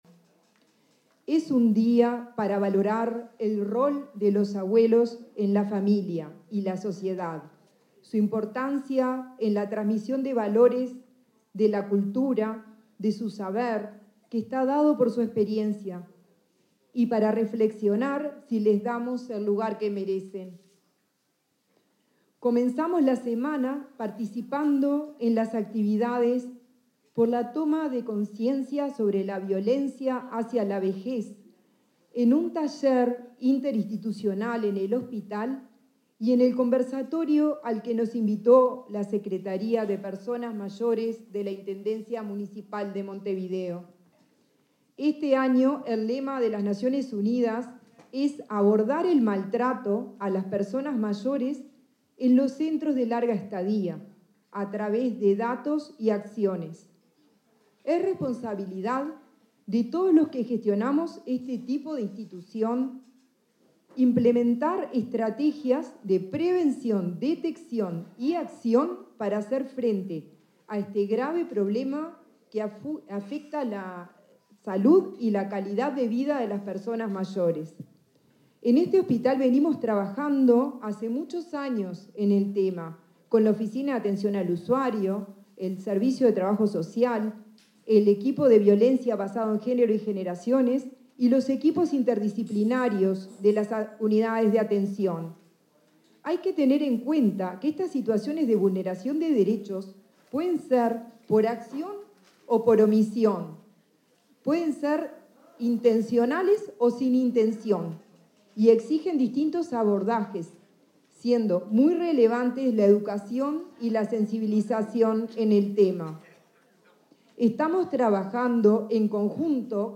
Palabras de autoridades de la salud